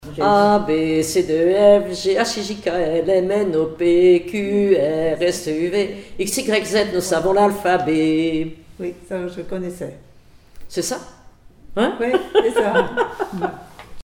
formulette enfantine : amusette
Comptines et formulettes enfantines
Pièce musicale inédite